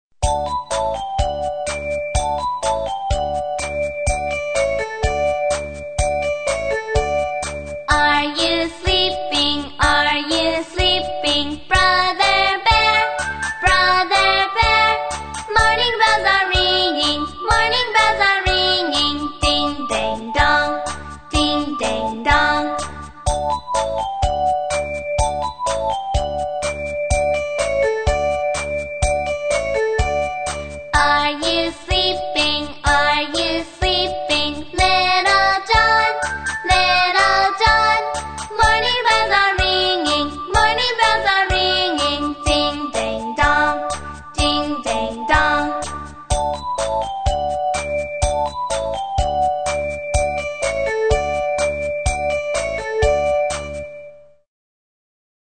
在线英语听力室英语儿歌274首 第7期:Are you sleeping的听力文件下载,收录了274首发音地道纯正，音乐节奏活泼动人的英文儿歌，从小培养对英语的爱好，为以后萌娃学习更多的英语知识，打下坚实的基础。